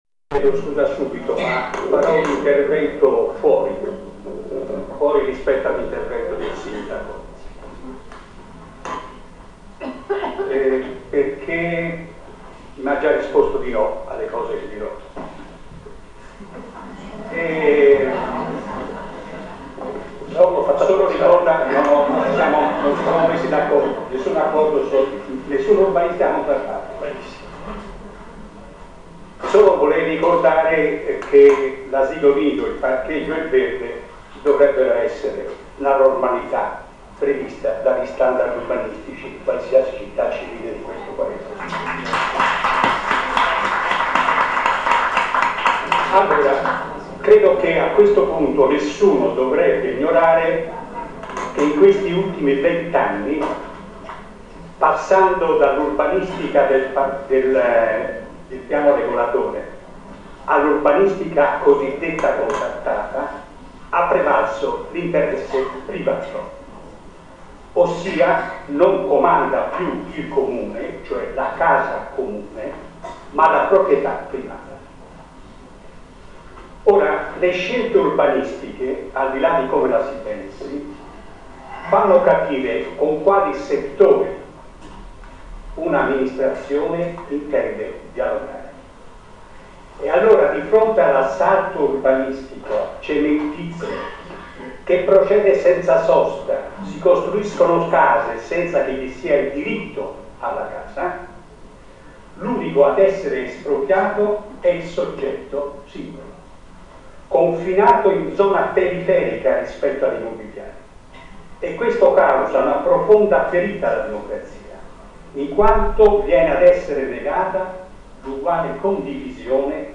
17.03.2010, l'ultima assemblea pubblica sull'Ex Panificio con la partecipazione del sindaco Renzi